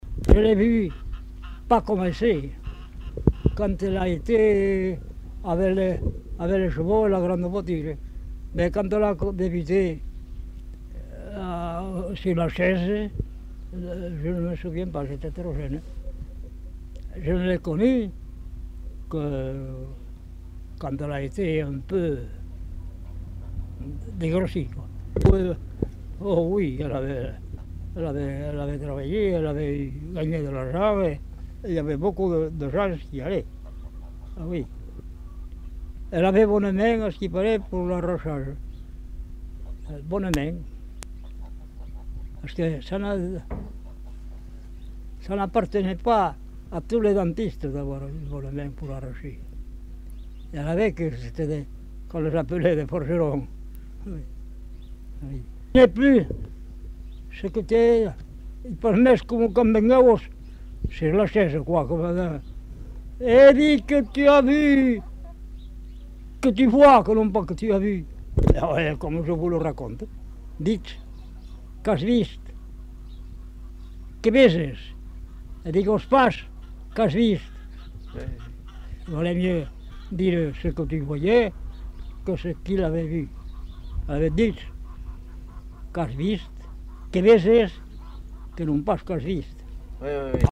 Lieu : Samatan
Genre : témoignage thématique